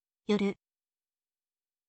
yoru